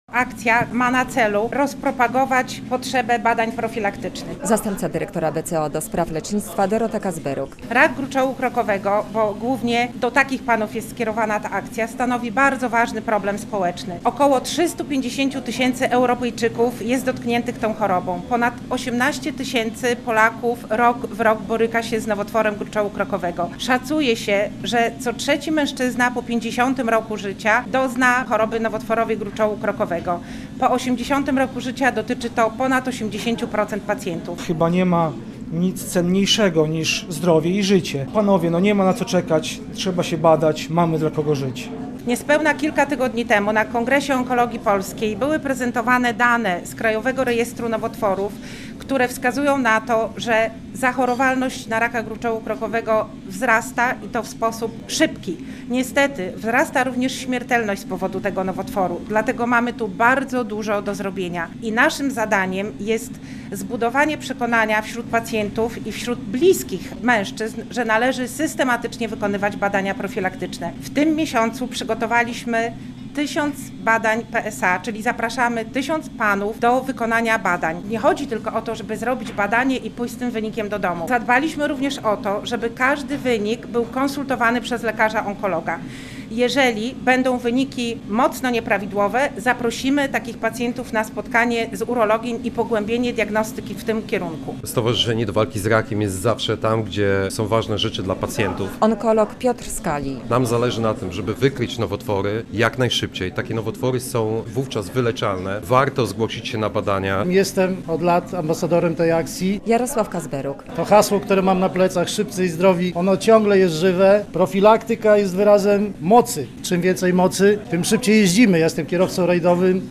Męski listopad - relacja